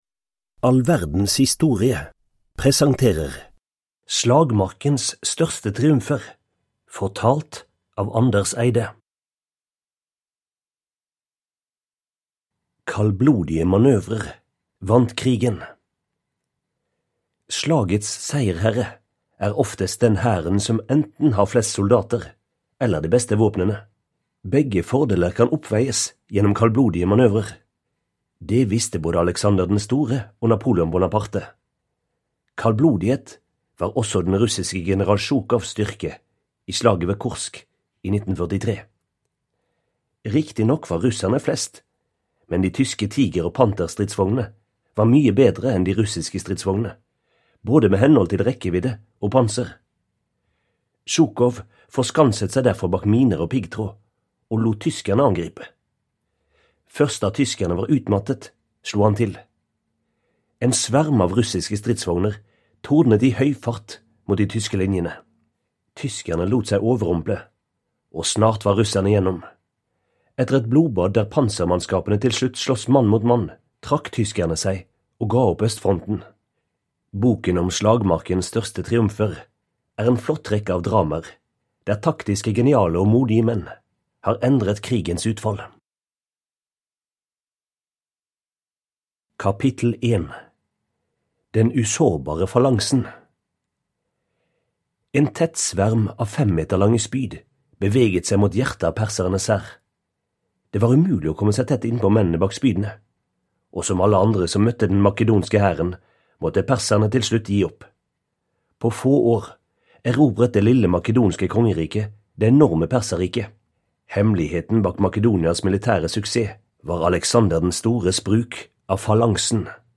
Slagmarkens største triumfer (ljudbok) av All verdens historie